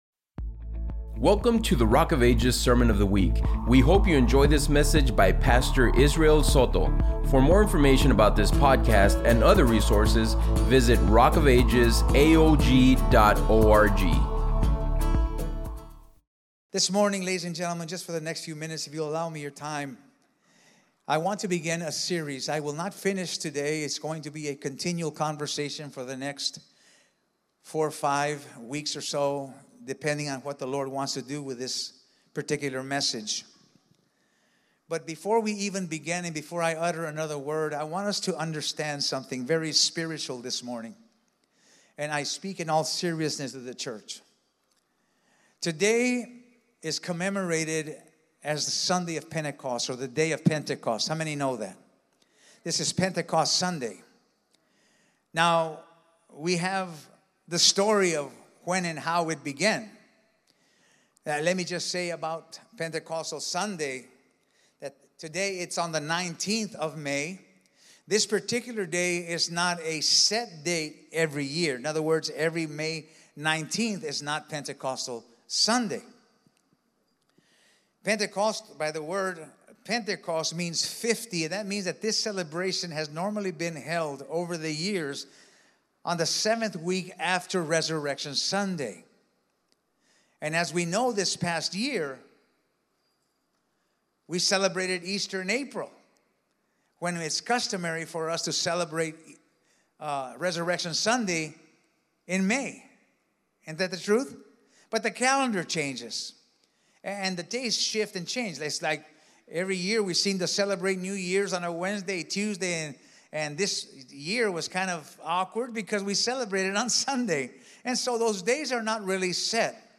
5-19-24-ENG-Sermion-Podcast.mp3